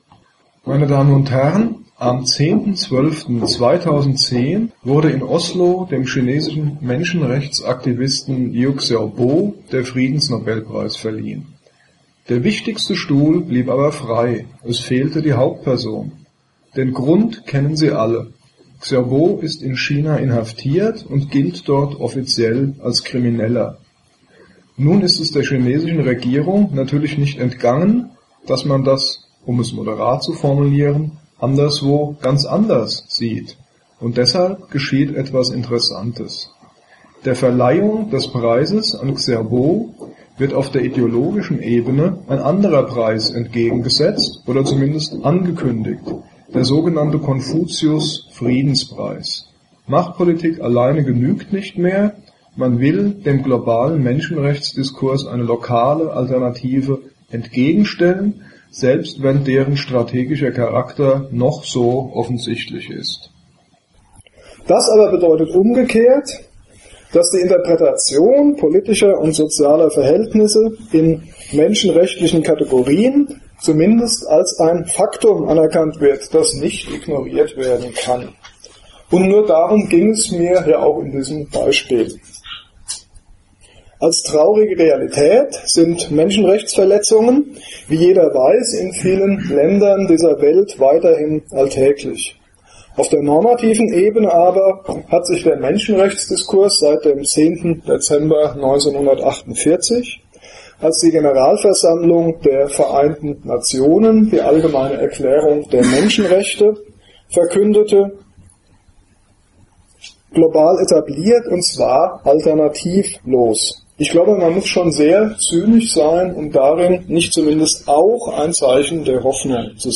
Antrittsvorlesung: Menschenrechte und die Frage ihrer anthropologischen Begründung